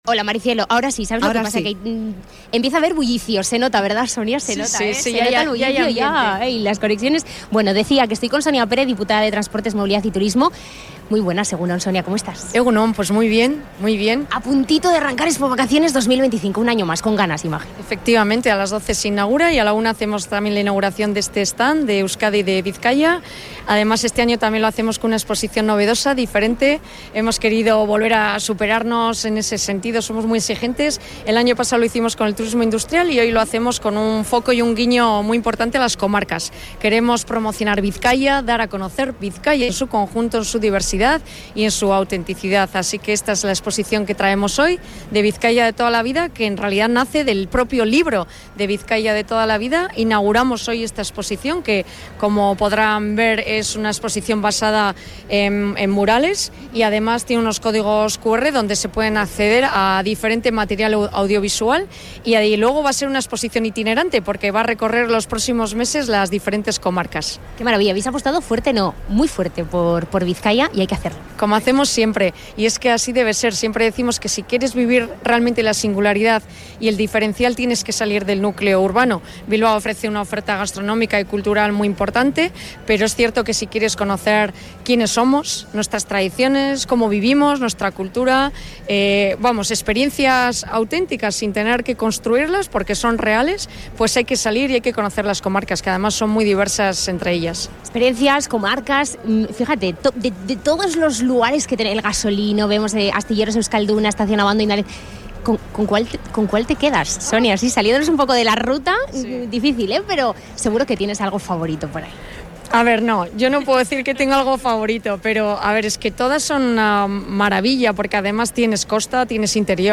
Hablamos con Sonia Pérez, diputada de Turismo, Transporte y Movilidad, en la Feria de Expovacaciones